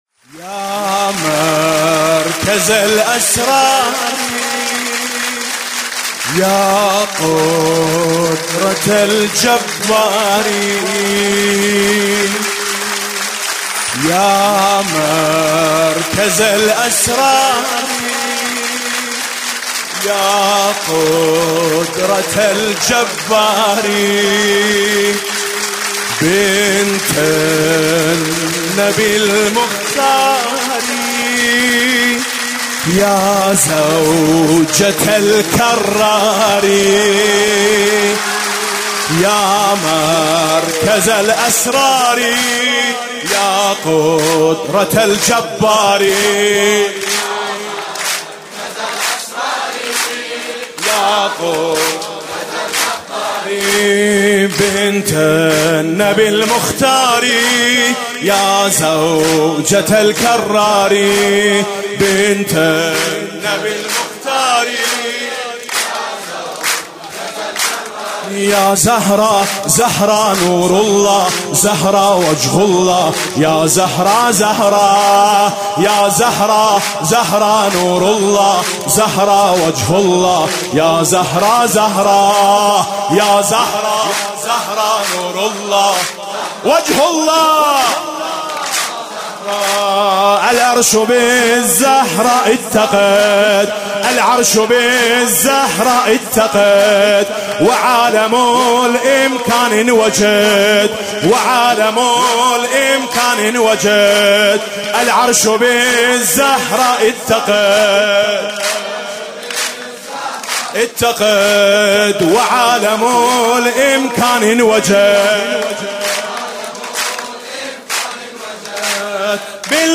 17 اسفند 96 - حسینیه محبان الصادق - سرود عربی - العرشُ بالزهراءِ اتَّقَدْ
ولادت حضرت زهرا (س)
سرود حاج میثم مطیعی